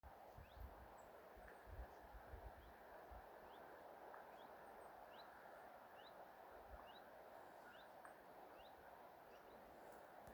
Čuņčiņš, Phylloscopus collybita
Administratīvā teritorijaRucavas novads